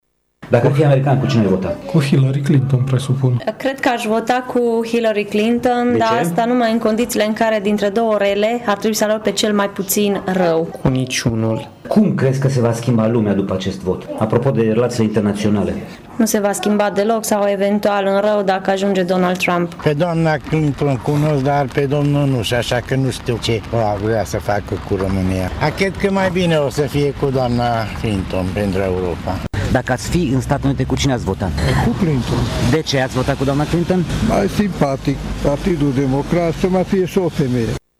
Unii târgumureșeni ar alege ”răul cel mai mic”, adică pe Hillary Clinton, dar cred că, indiferent cine va fi președinte, Europa și România nu vor fi foarte influențate: